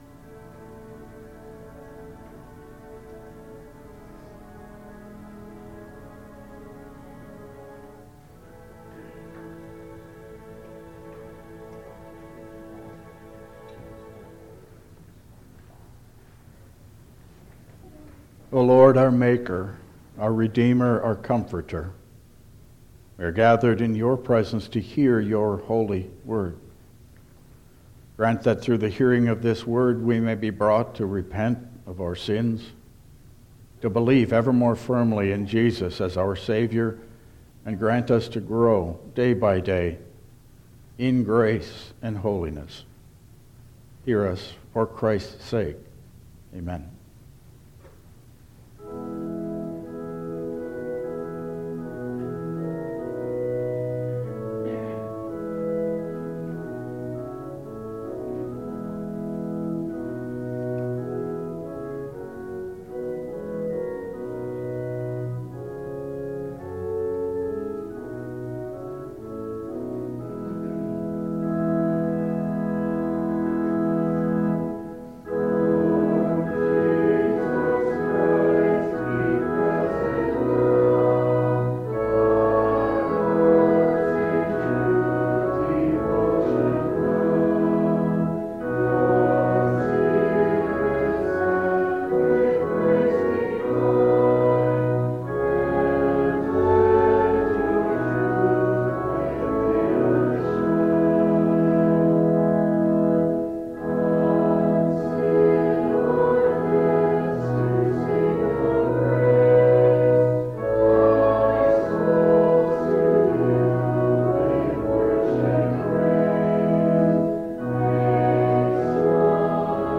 Download Files Printed Sermon and Bulletin
Service Type: Regular Service